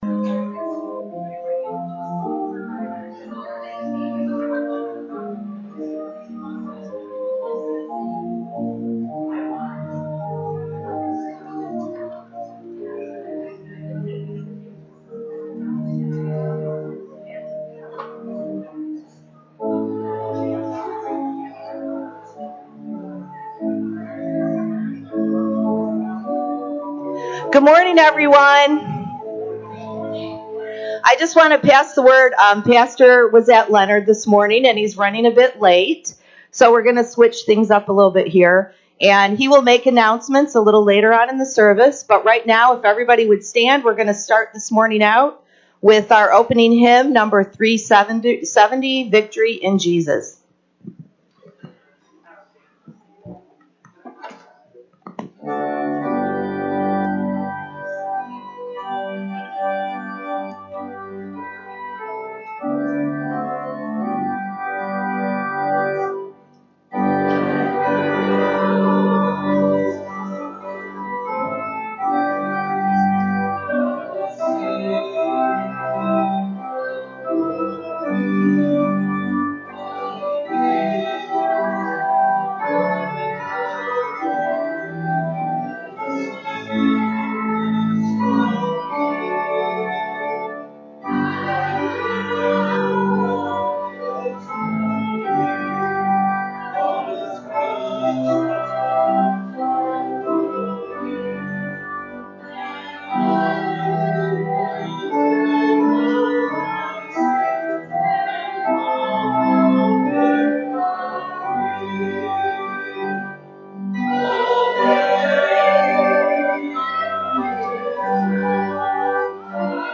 RUMC-service-Oct-1-2023-CD.mp3